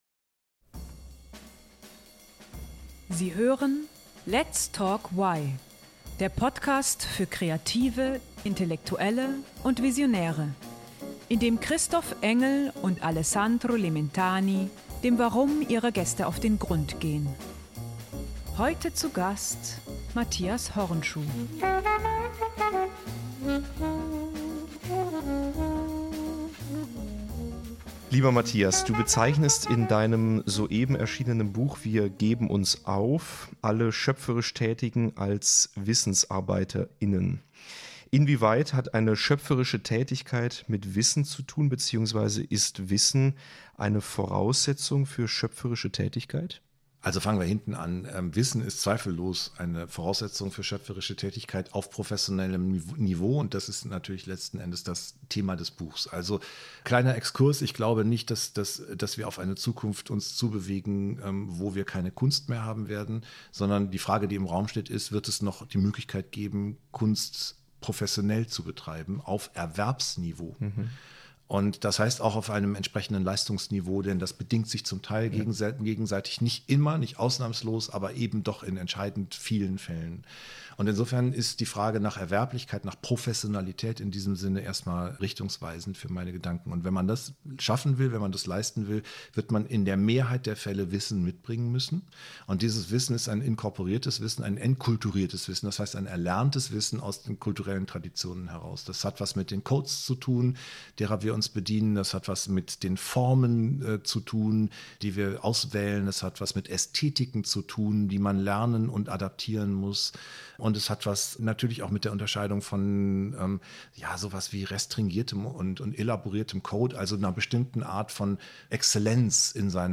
Das Interview wurde am 15.09.2025 aufgezeichnet.